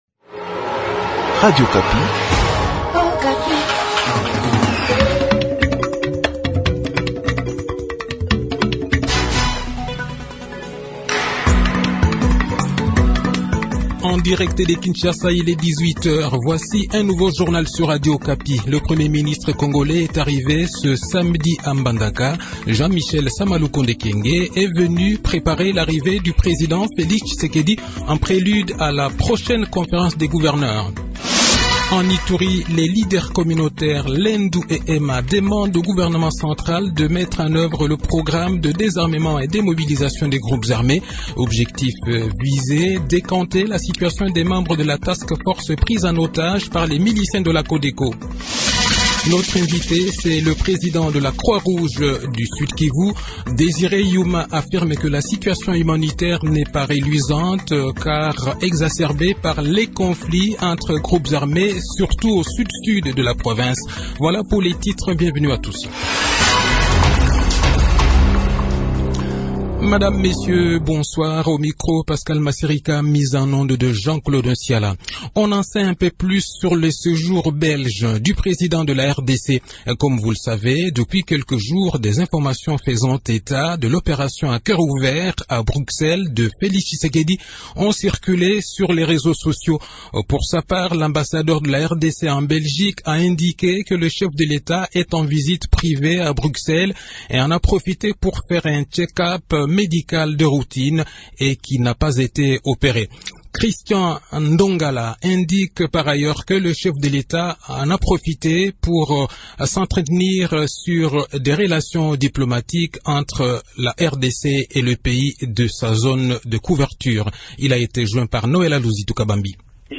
Le journal de 18 h, 12 mars 2022